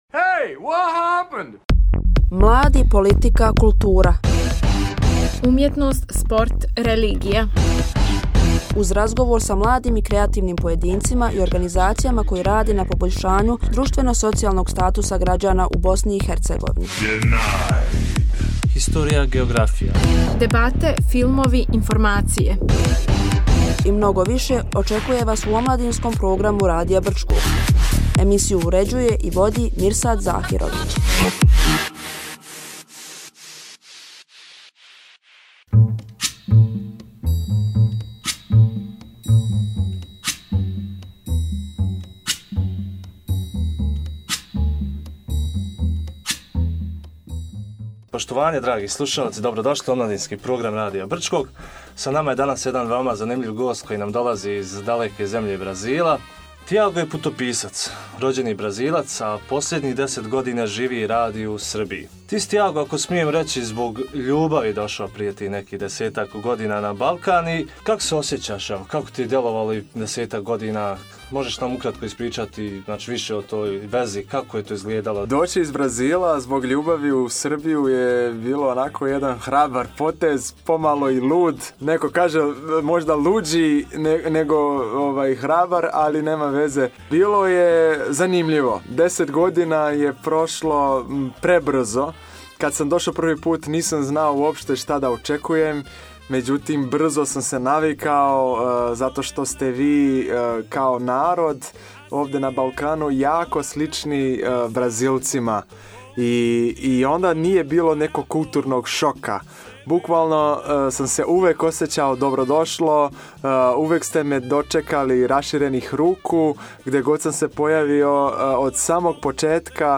Gost “Omladinske emisije”